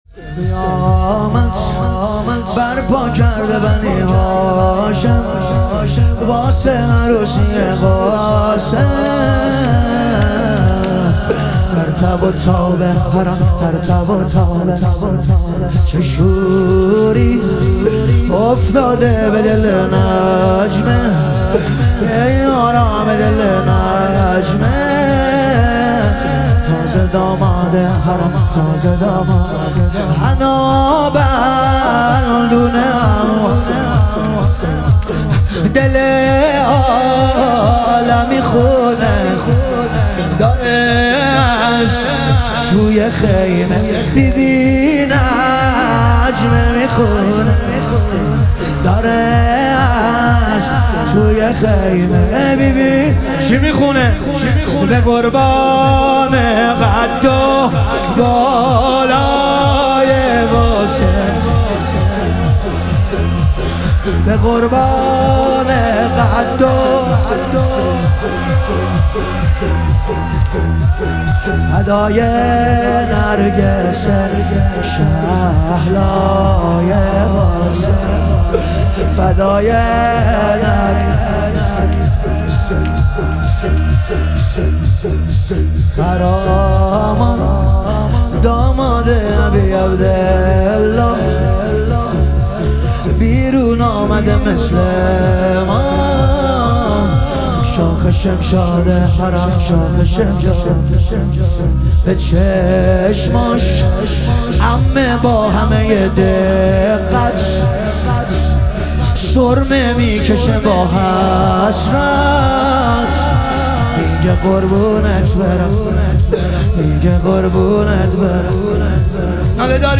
ظهور وجود مقدس حضرت قاسم علیه السلام - لطمه زنی